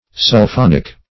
Sulphonic \Sul*phon"ic\, a. (Chem.)